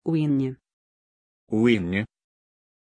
Pronunciation of Winni
pronunciation-winni-ru.mp3